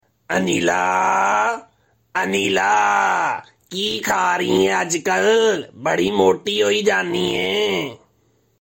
Goat Calling Aneela Name Funny Sound Effects Free Download